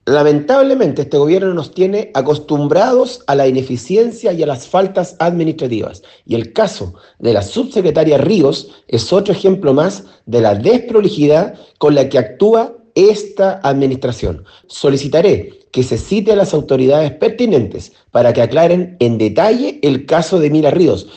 Asimismo, el diputado UDI, Marco Antonio Sulantay, presionó por una rendición de cuentas del ejecutivo ante la instancia para despejar las dudas del caso, acusando que este sería “otro ejemplo más de la desprolijidad con la que actúa esta administración”.